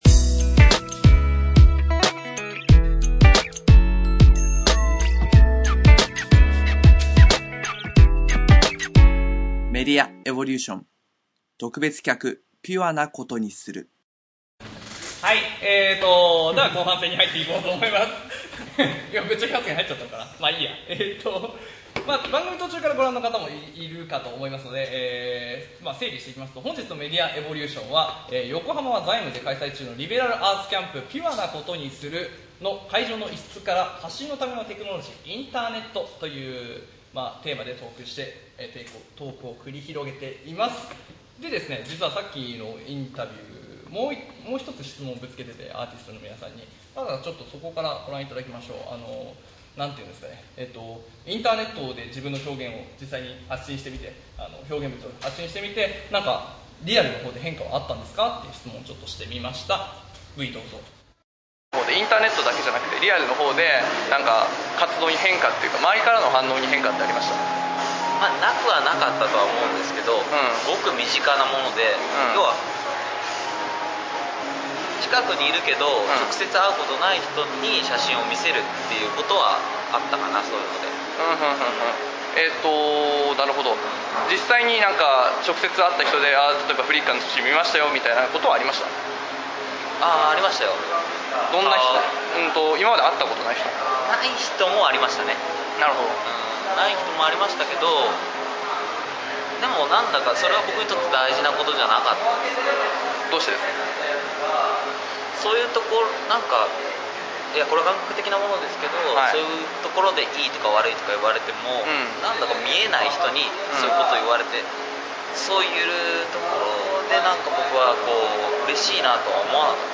今回配信のvol.1では、学校教育の現状と課題について語り合います。 前回と打って変わってマジメな雰囲気！………になるハズもなく、いつも通りの、いかにも「メディエヴォ」なテンションをお楽しみくださいwww